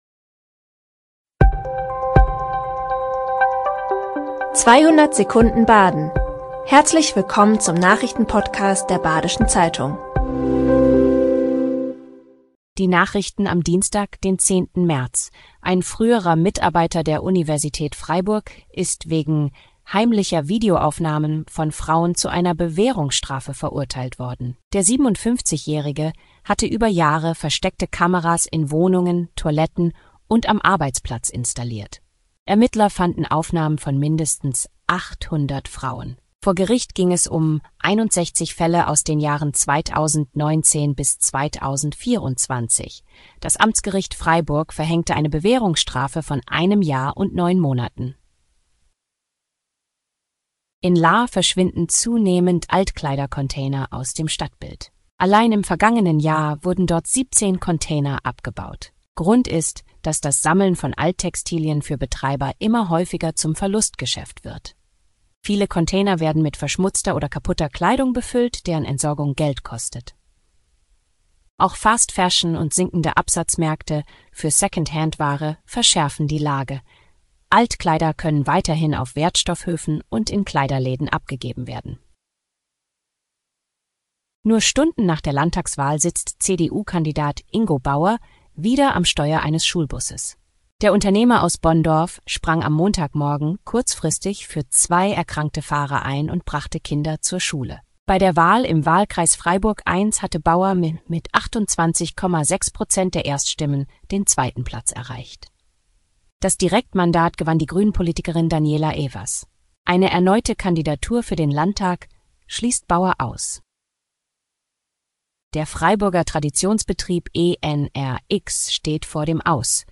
5 Nachrichten in 200 Sekunden.
Nachrichten